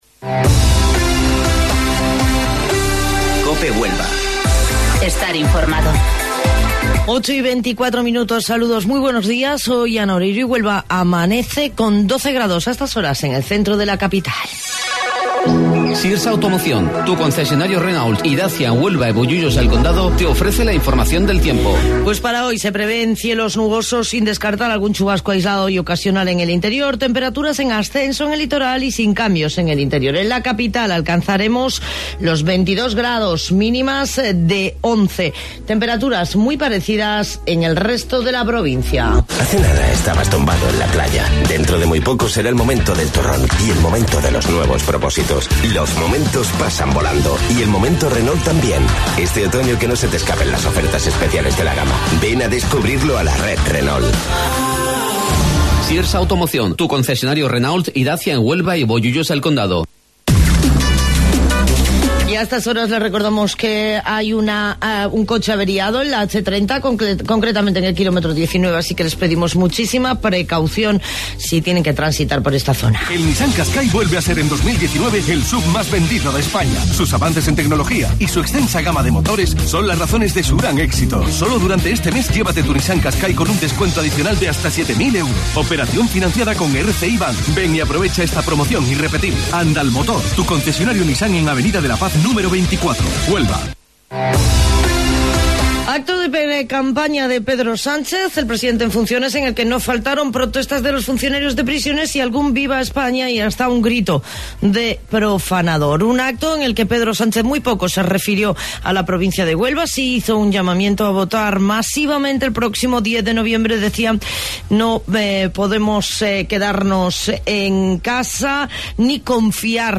AUDIO: Informativo Local 08:25 del 23 de Octubre